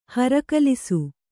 ♪ harakalisu